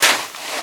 High Quality Footsteps
STEPS Sand, Walk 30.wav